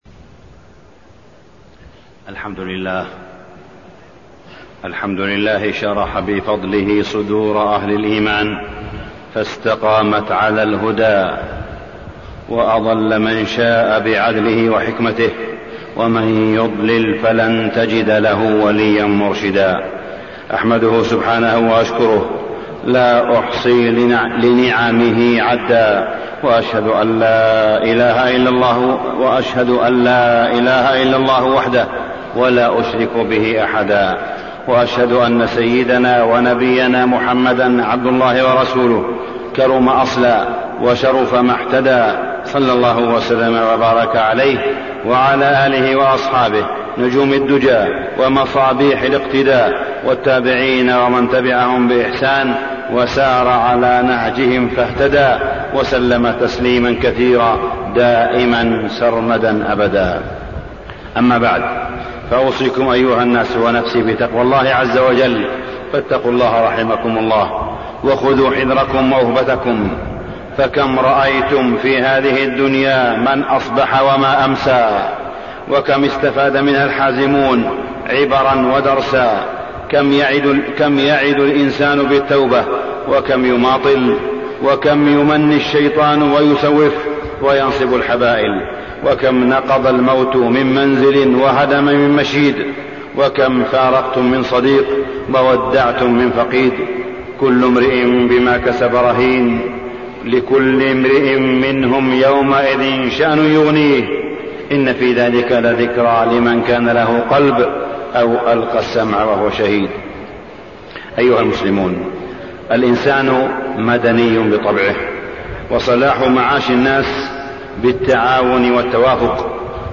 تاريخ النشر ١٤ جمادى الآخرة ١٤٢٨ هـ المكان: المسجد الحرام الشيخ: معالي الشيخ أ.د. صالح بن عبدالله بن حميد معالي الشيخ أ.د. صالح بن عبدالله بن حميد رفع الريبة عن جهاز الحسبة